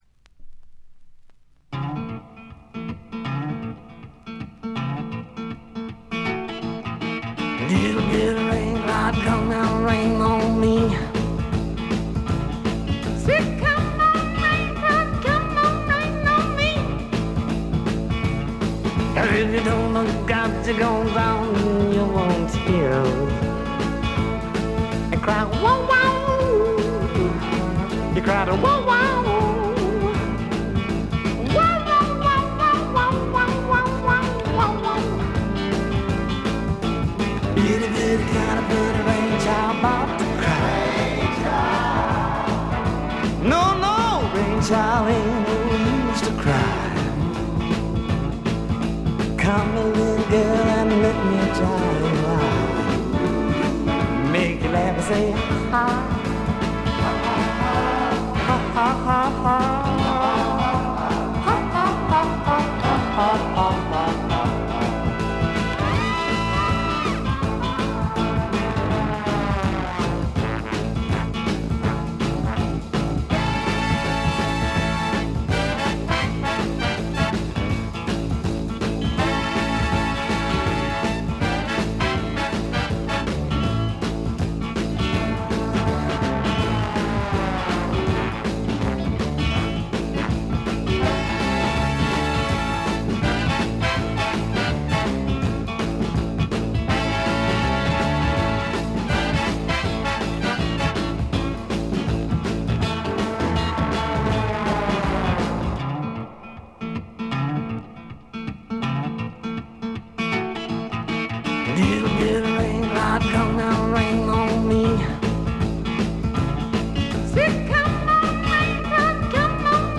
ほとんどノイズ感無し。
文句なしのスワンプ名盤。
試聴曲は現品からの取り込み音源です。